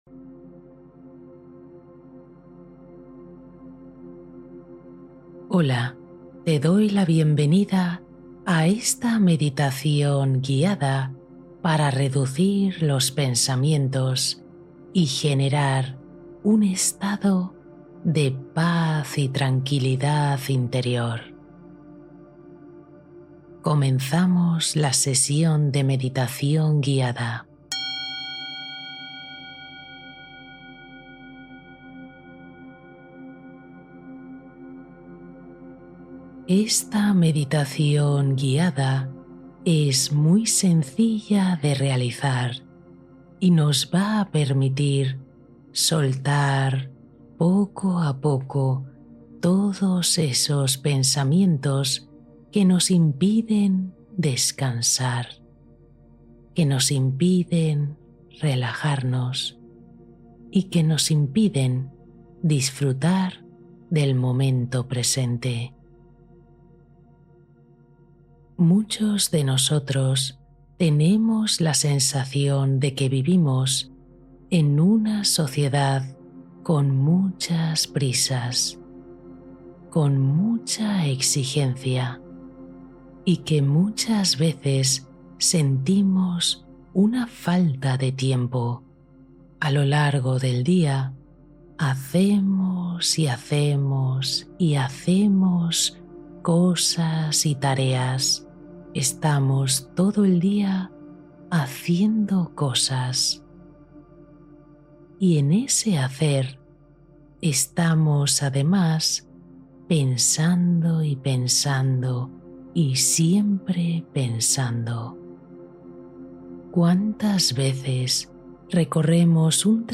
Meditación para dormir en paz, relajar el cuerpo y disolver ansiedad